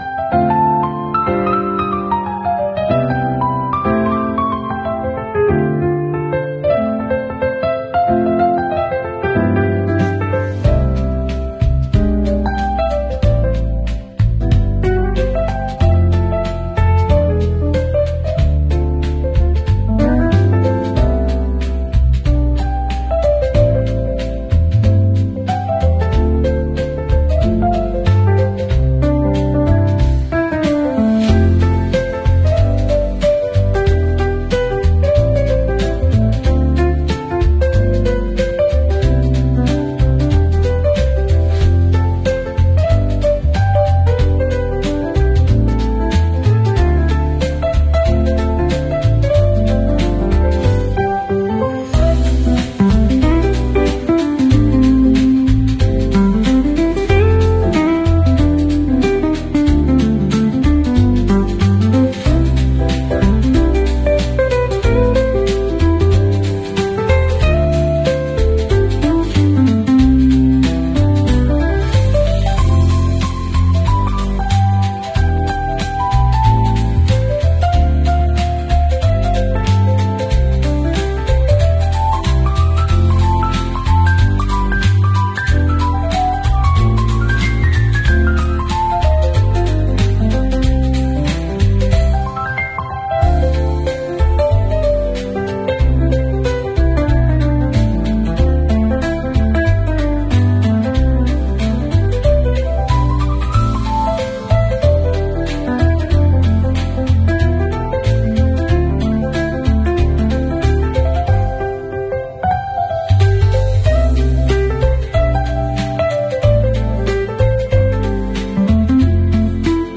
Perfect for study breaks, creative Reels, or chill vibes.